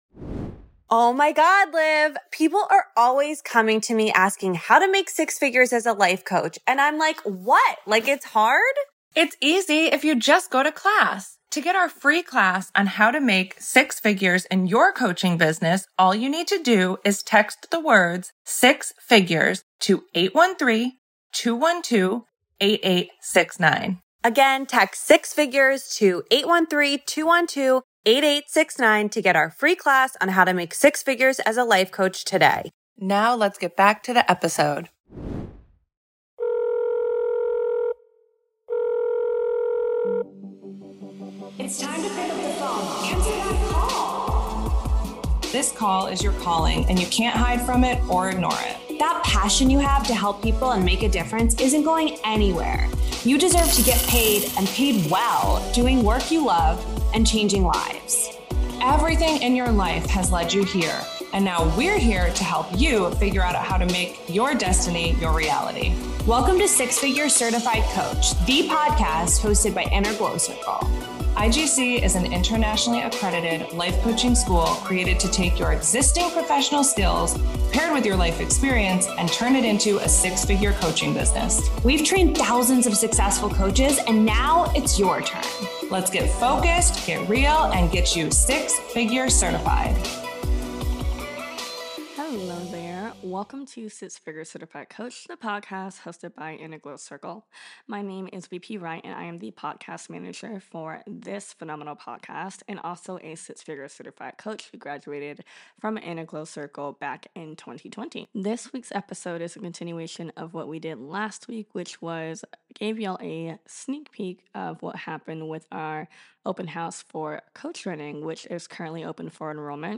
In this week's episode, we're diving deeper into the replay from IGC's Live Open House.